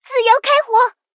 T44开火语音2.OGG